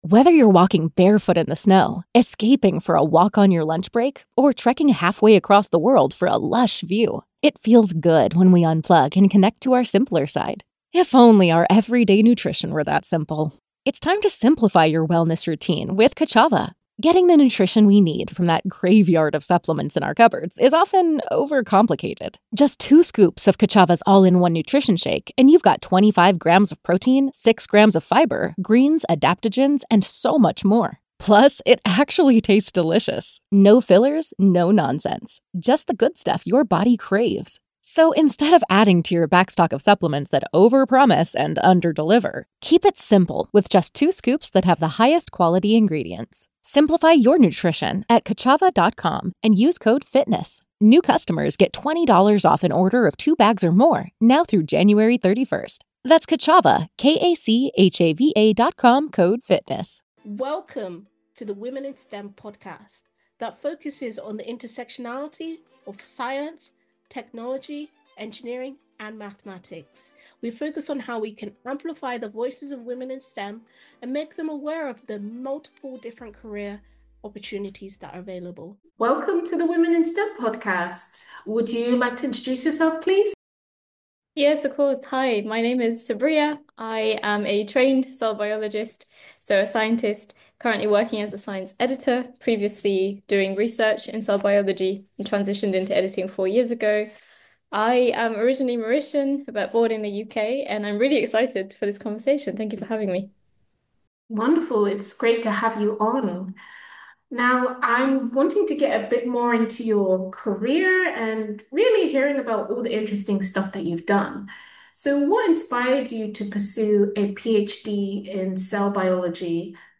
In this honest conversation, we bust common PhD myths, explore what rigorous science really means, and tackle the confidence questions that keep so many brilliant minds from pursuing their dreams.